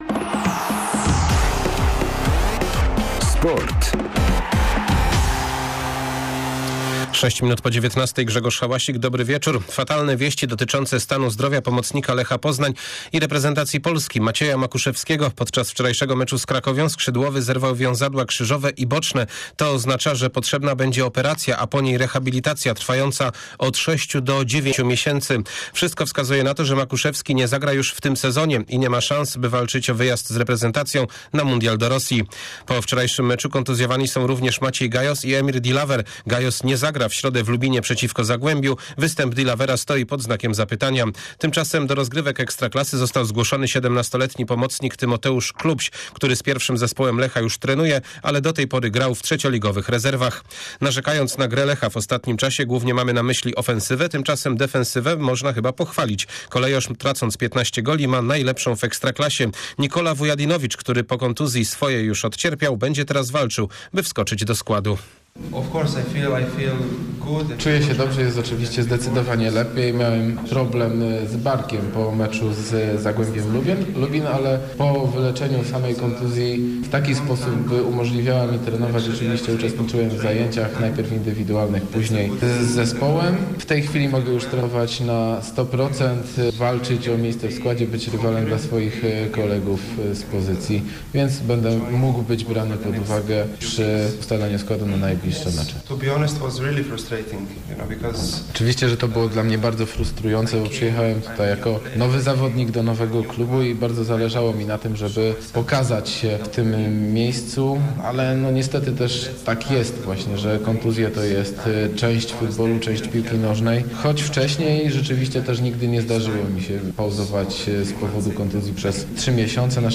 11.12 serwis sportowy godz. 19:05